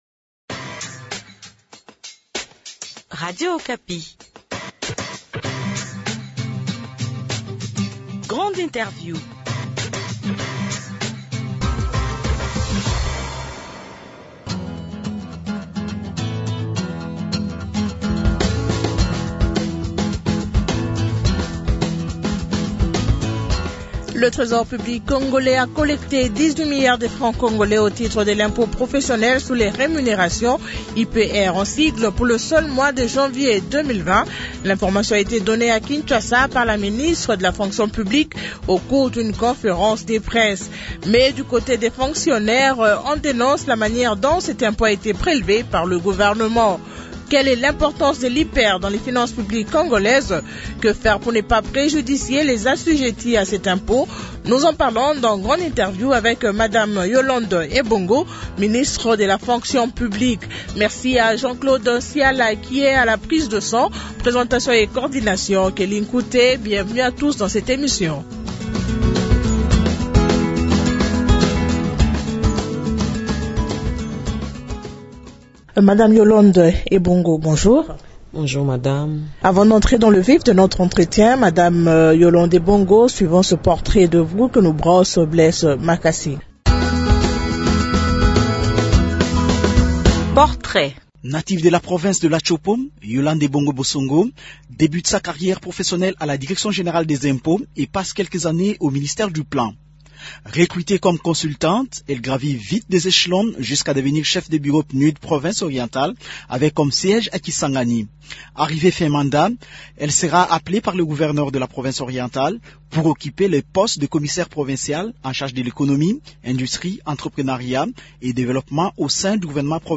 Dans cet entretien, Yolande Ebongo évoque aussi la question sur le processus de rajeunissement de la fonction publique congolaise.